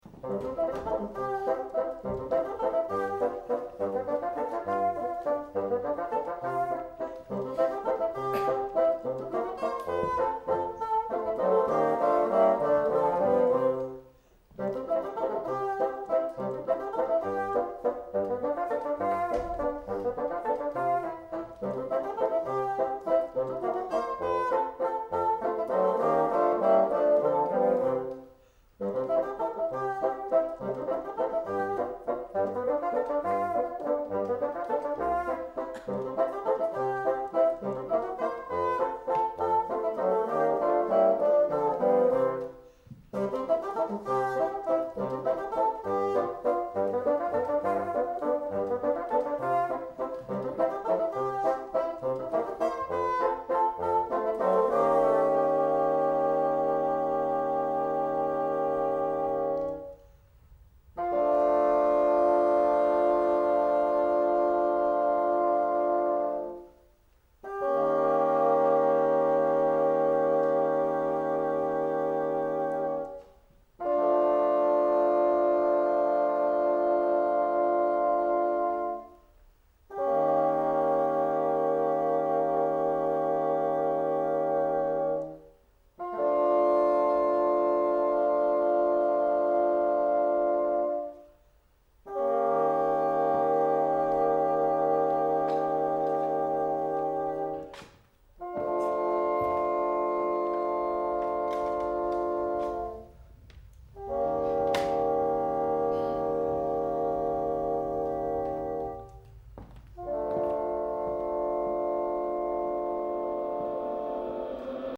This is the version for four bassoons from the song cycle 'Schaduwee'; it has also appeared in several other recent works of mine, in various arrangements, usually performed simultaneously with other material.
The idea of all of these pieces is that they all appear completely innocent, until they suddenly get stuck on a particularly dodgy cadence.